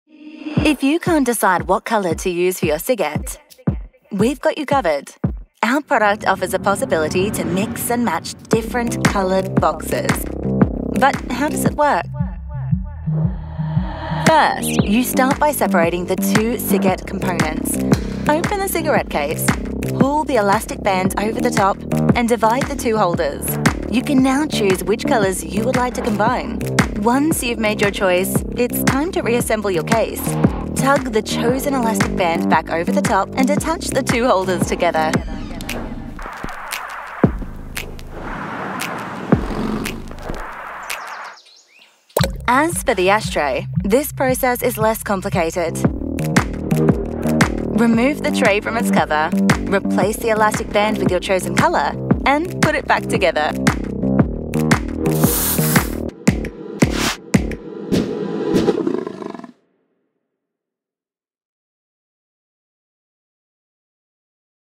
Young Adult, Adult, Teenager, Child, Mature Adult
Has Own Studio
Ciget_Explainer.mp3